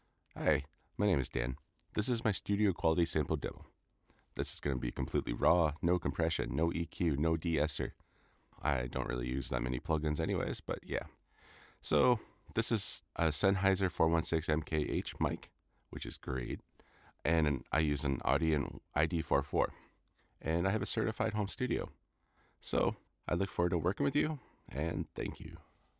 Adult (30-50) | Older Sound (50+)
0824studio_raw_sample.mp3